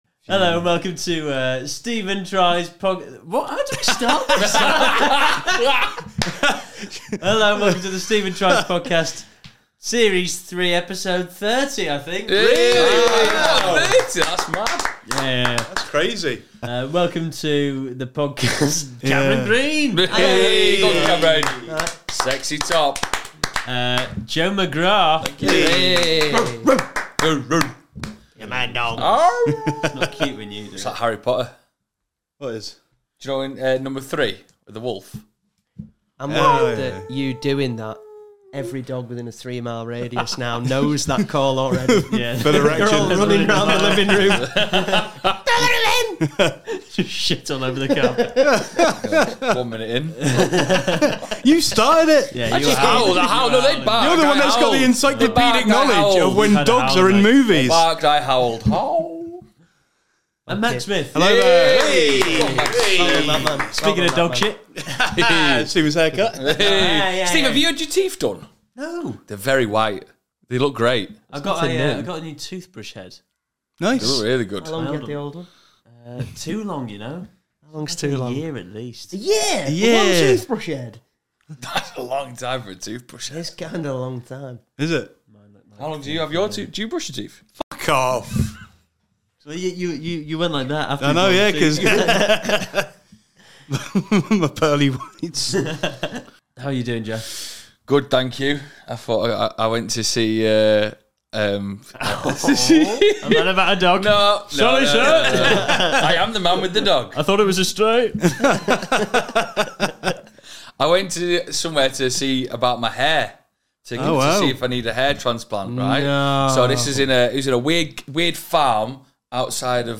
Comedy Interviews, Comedy